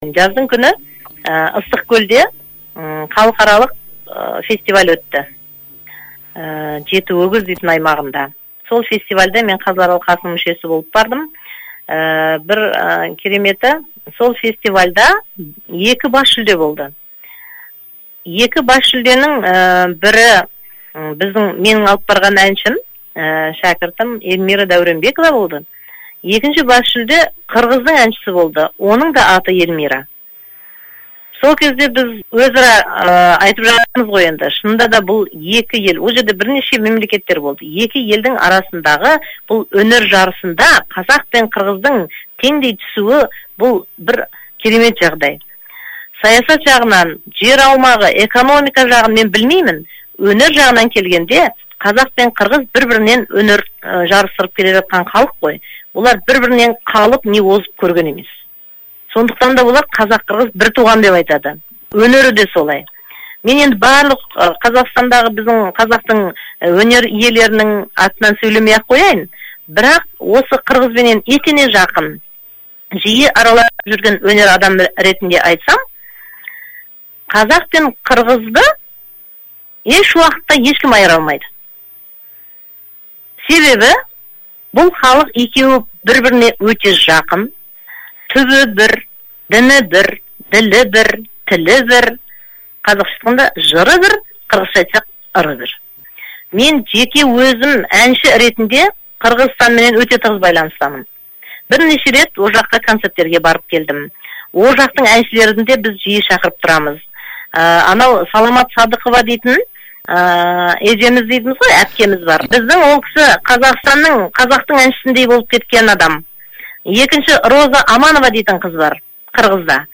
қазақ-қырғыз достығы туралы сөзі